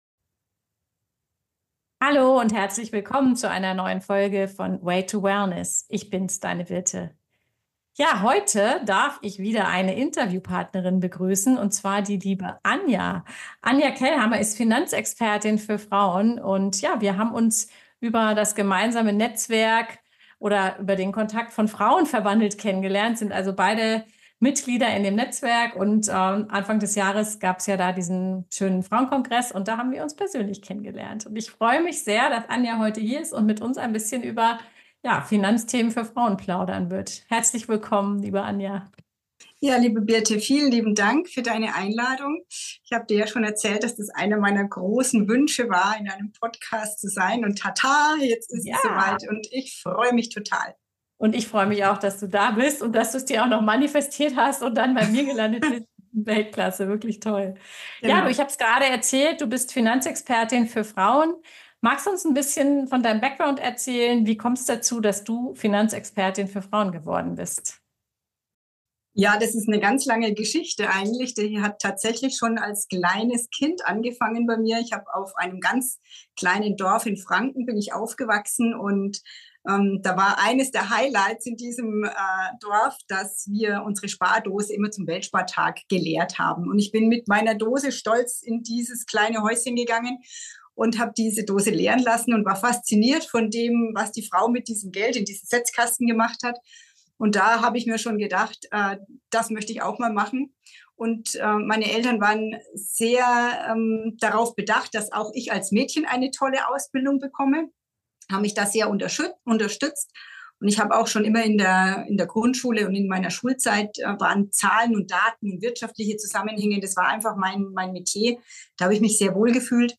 Frauen und Finanzen – Interview